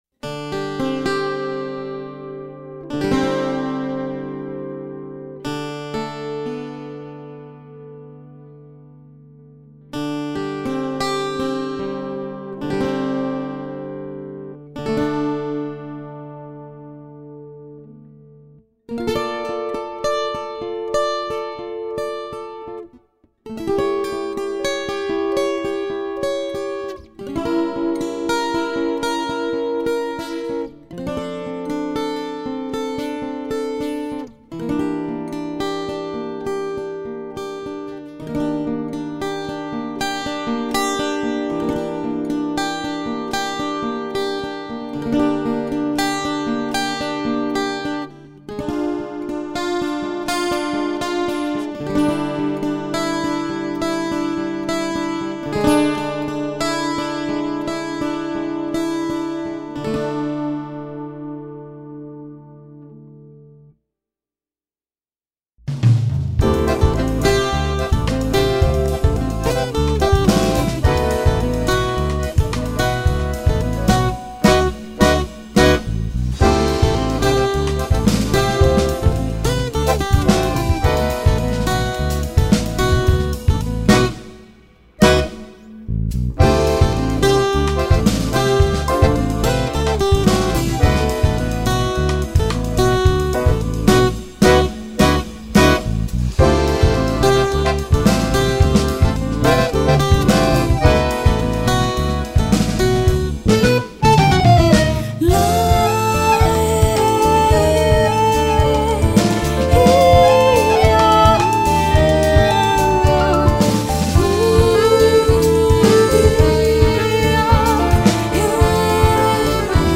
2807   06:05:00   Faixa: 5    Jazz
Bateria, Percussão
Baixo Elétrico 6, Violao Acústico 6
Guitarra, Viola
Acoordeon